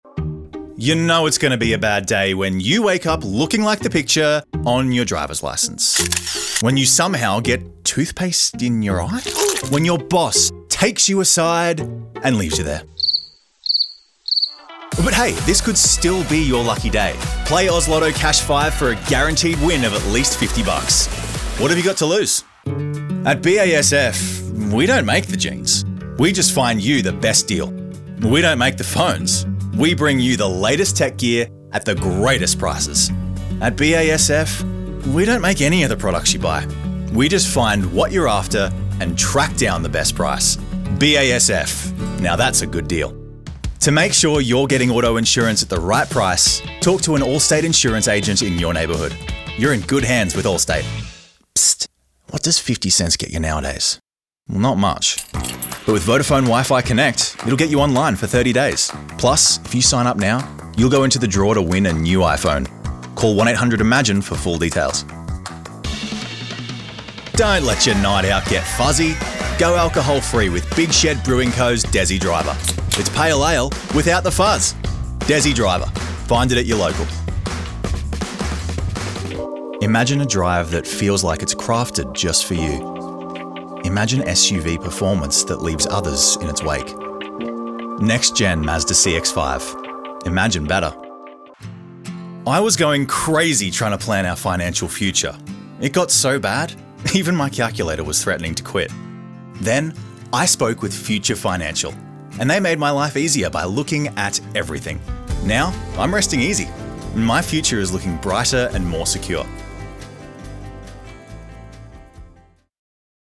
I can sound like the relatable guy next door. Conversational, warm, natural , trustworthy and with empathy .Or I can give a voiceover some sell....
0820Commercial_Demo_Reel.mp3